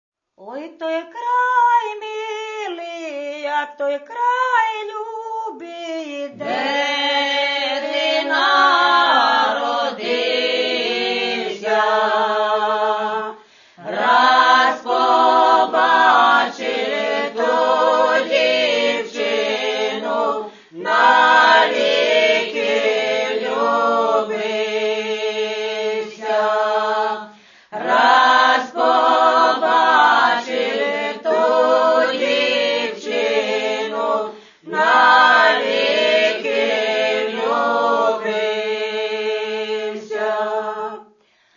Традиційні ліричні пісні Полтавщини.